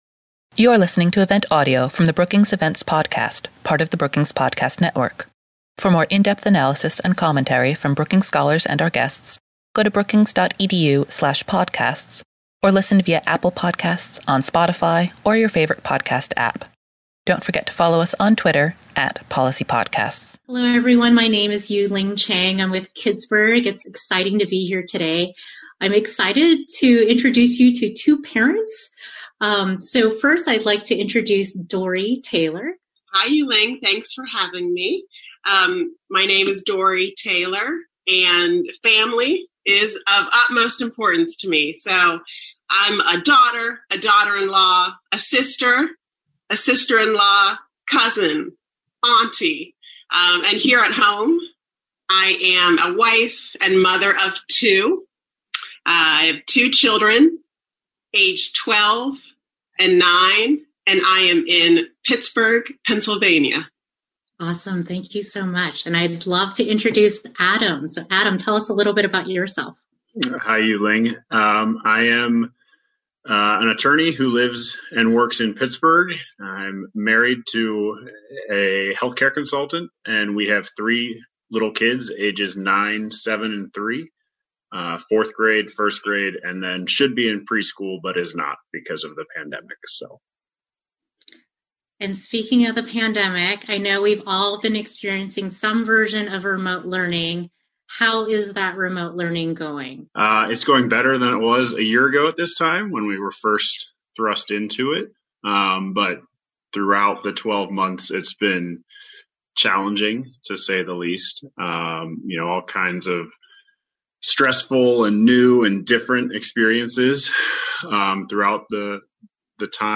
On March 16, CUE hosted a webinar to share the findings of its parent surveys and consider how the new voices and altered power dynamics could lead to systems transformation. After a presentation of the survey findings, there was a moderated discussion with members of the Family Engagement in Education Network.